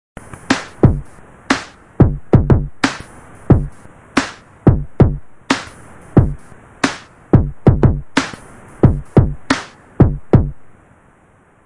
musical parts " Smooth Drum Loop 110bpm
描述：一个鼓点，以110 bpm循环播放
Tag: 桶循环 110-BPM 鼓节拍